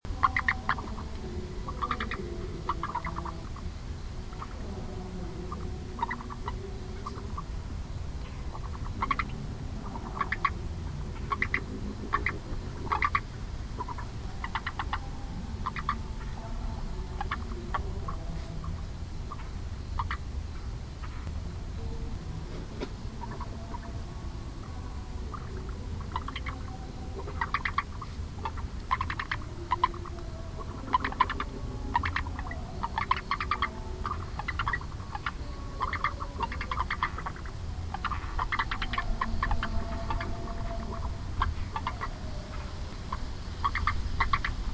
長腳赤蛙 Rana longicrus
錄音地點 新北市 五股區 御史路旗竿湖菜園
錄音環境 水池邊的菜園內
行為描述 鳴叫